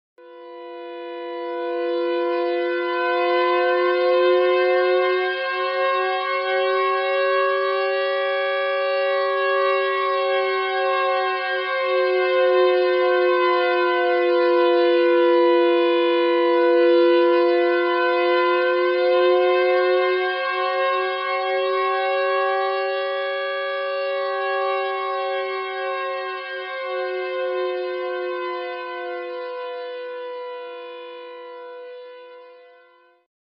На этой странице собраны звуки сиреноголового — жуткие аудиозаписи, создающие атмосферу страха и неизвестности.
Звук сирены в стартовой заставке Siren Head Horror game